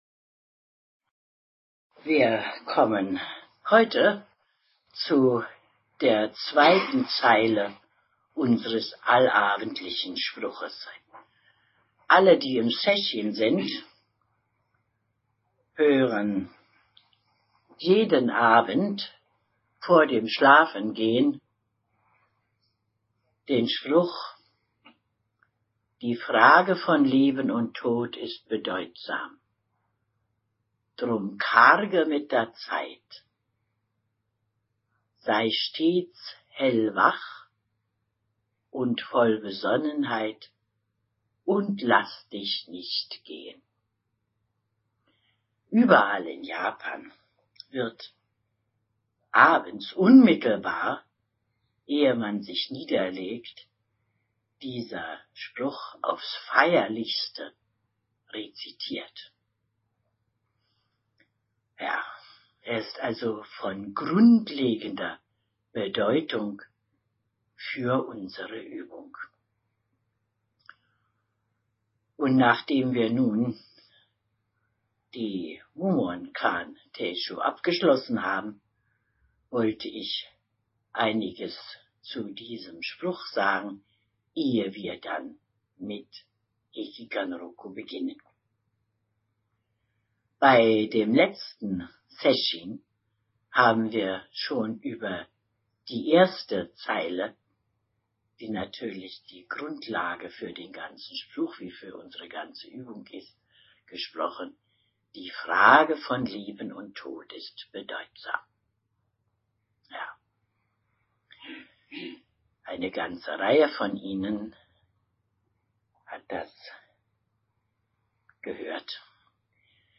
Das Buch enthält die schriftliche Version, die CDs die aus dem Moment heraus gehaltene mündliche Version, welche damit ganz in der Tradition des Lehrens im Zen-Buddhismus steht.
3-Zen-Teisho auf 3 CD (BOX)